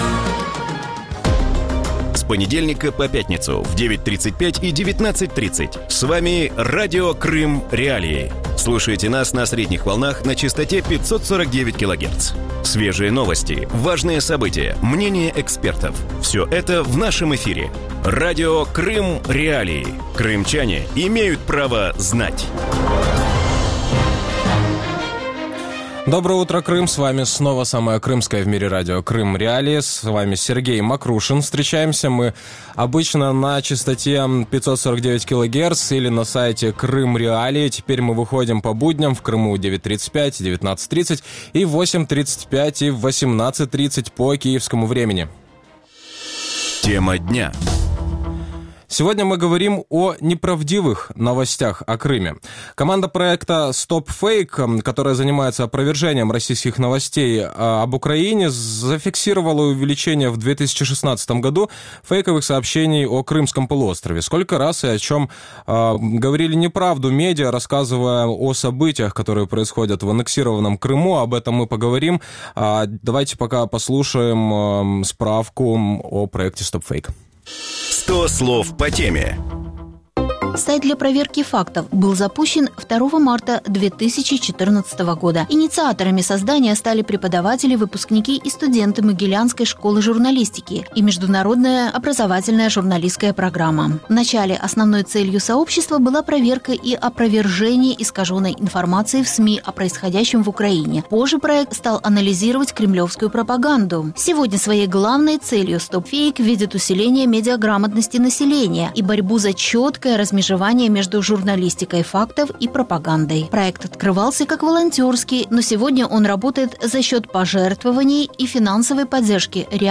Утром в эфире Радио Крым.Реалии говорят о неправдивых новостях СМИ о Крыме.